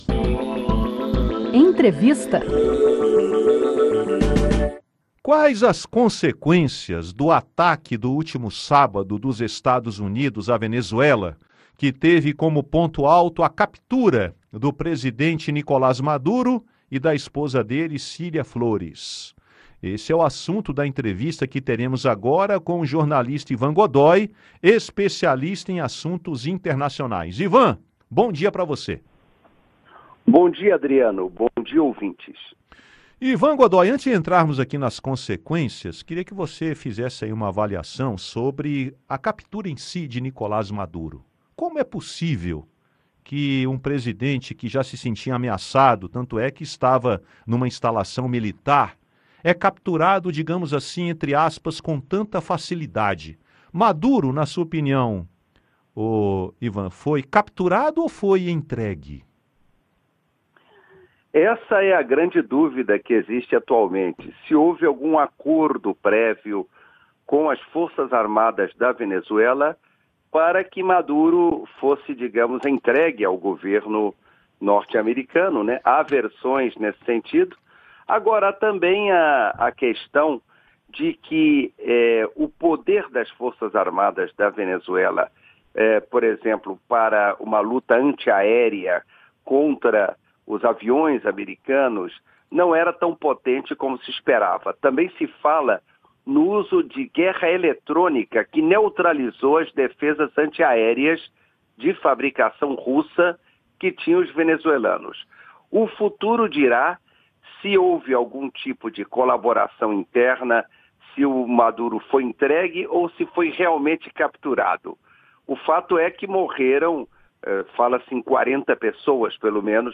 A captura do presidente venezuelano Nicolás Maduro e da esposa dele, Cilia Flores, durante uma operação dos Estados Unidos no último sábado (3), levanta dúvidas sobre os desdobramentos políticos e diplomáticos na Venezuela. Em entrevista à Rádio Senado